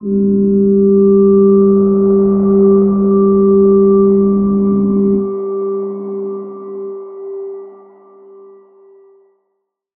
G_Crystal-G4-f.wav